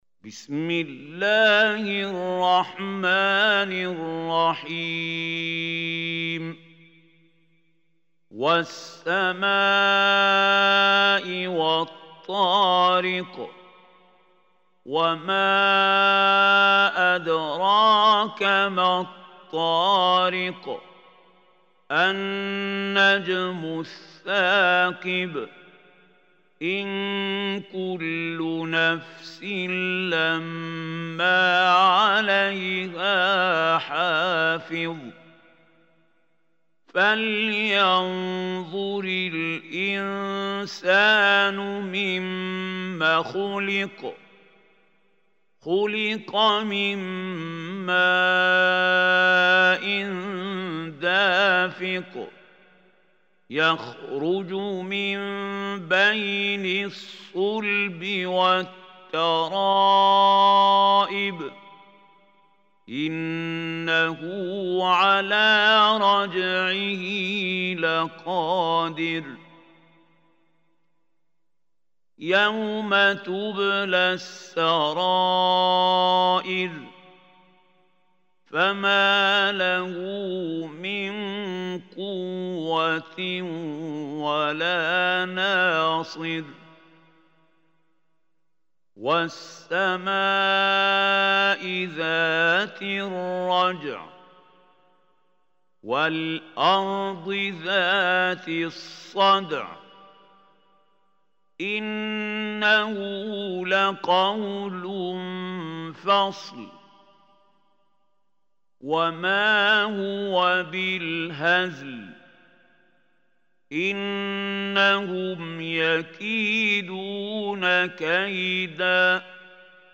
Surah At-Tariq, listen or play online mp3 tilawat / recitation in Arabic in the beautiful voice of Sheikh Mahmoud Khalil Hussary.